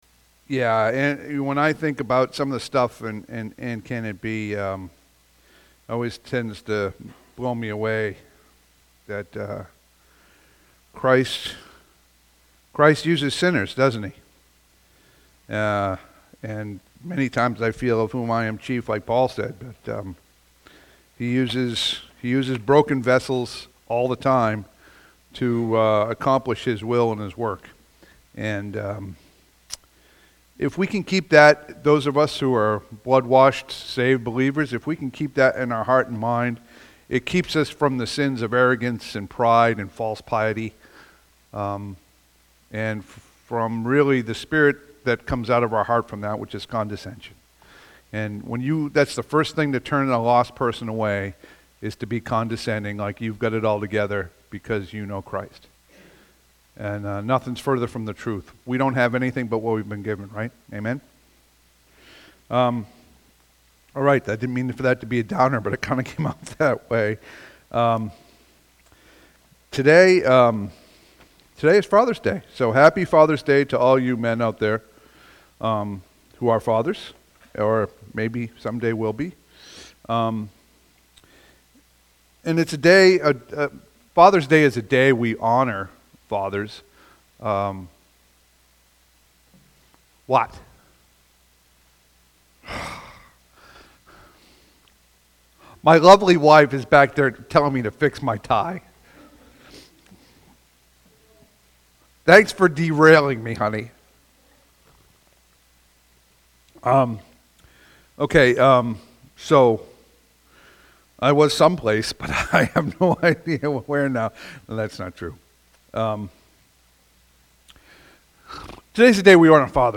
Passage: Ephesians 5:25-33 Service Type: Sunday AM « June 8